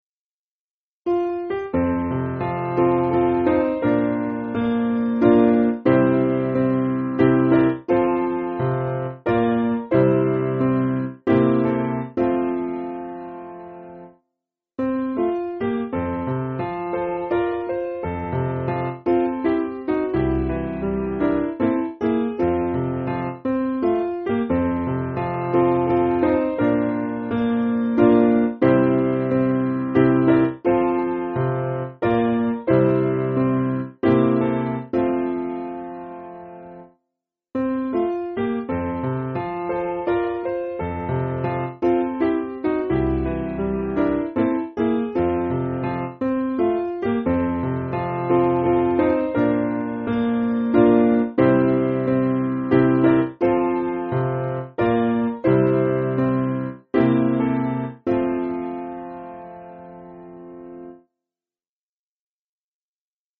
Simple Piano